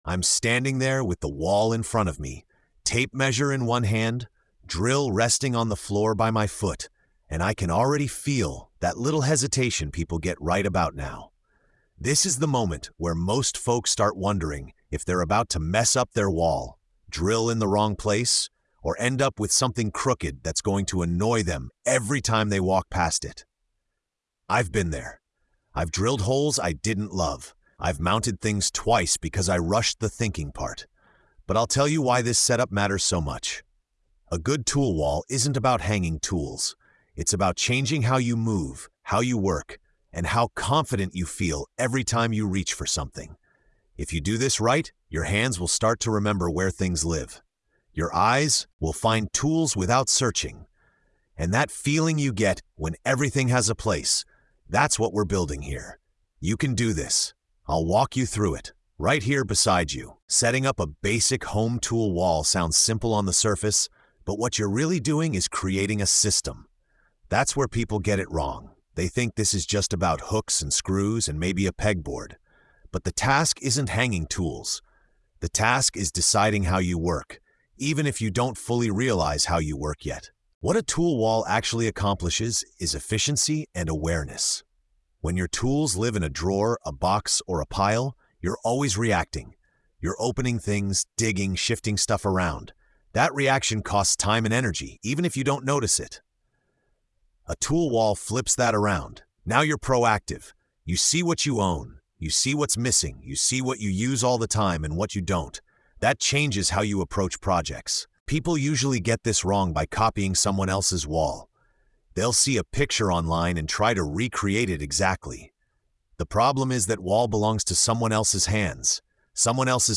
Through calm guidance, lived mistakes, and professional judgment, the episode shows how a properly planned tool wall can change not just workflow, but mindset. This is not about hardware or hooks—it’s about building a system that supports every project that follows.
The tone is grounded, practical, and quietly empowering, reminding the listener that craftsmanship starts long before the first real job begins.